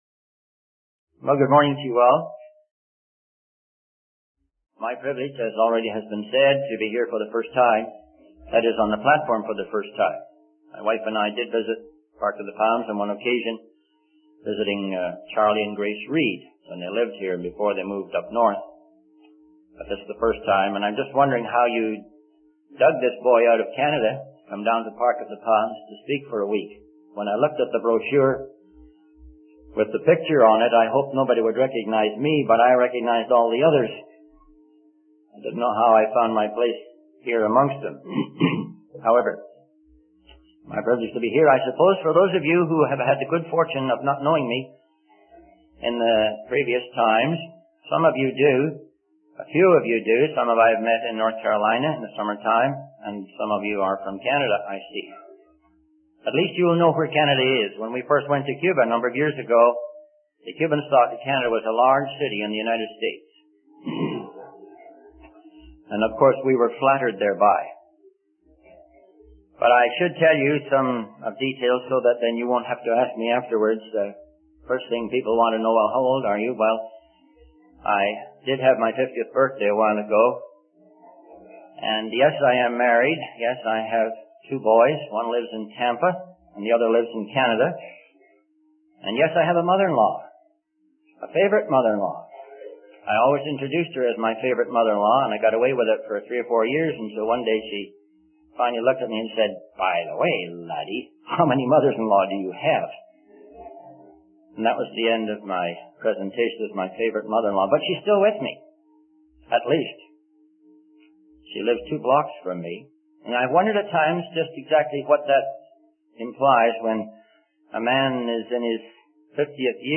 In this sermon, the preacher shares a personal story of a man who was persecuted for holding religious gatherings in his home.